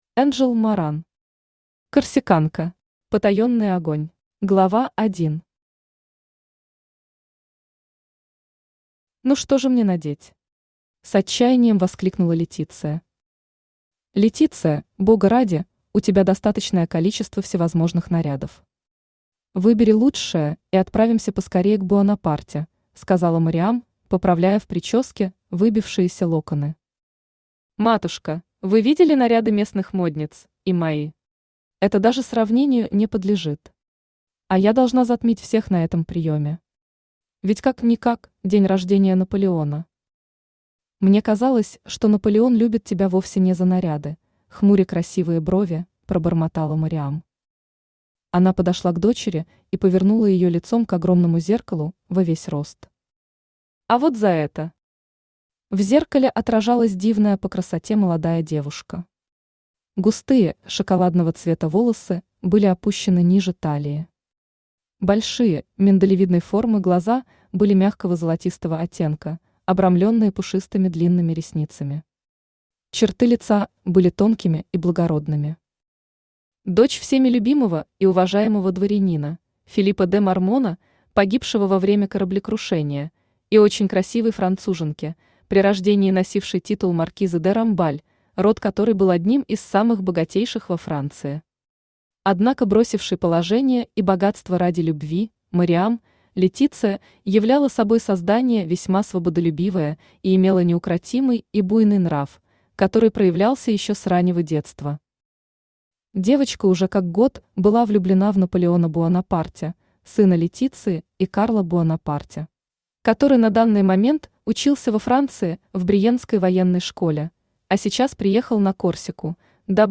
Аудиокнига Корсиканка. Потаенный огонь | Библиотека аудиокниг
Aудиокнига Корсиканка. Потаенный огонь Автор Энджел Моран Читает аудиокнигу Авточтец ЛитРес.